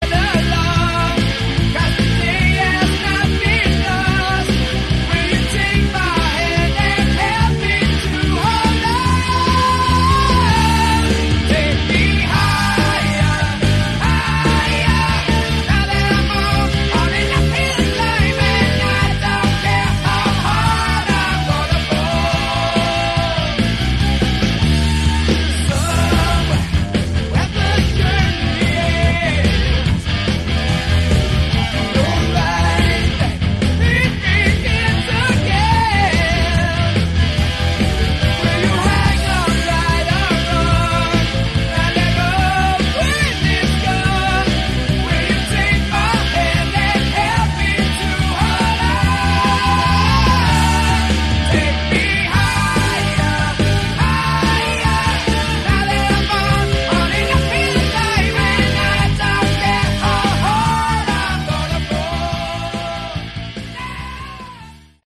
Category: Hard Rock
lead and backing vocals
keyboards
guitar
drums